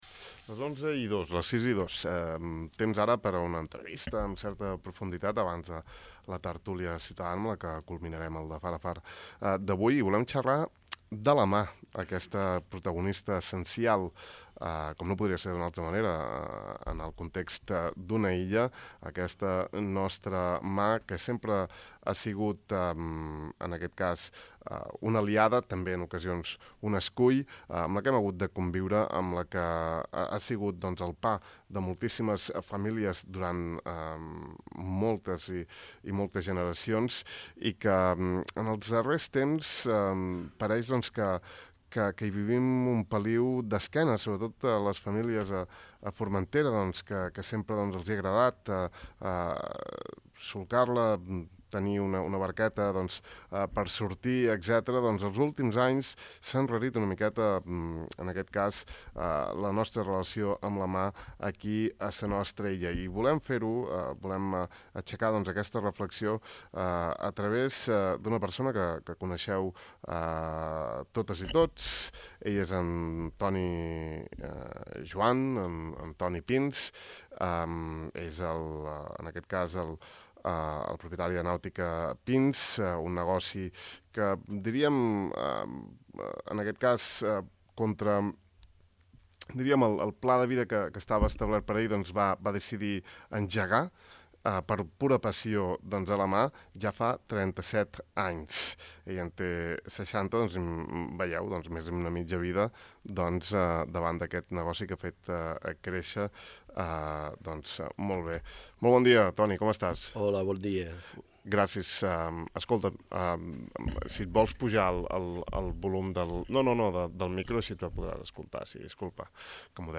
Aquest matí hem conversat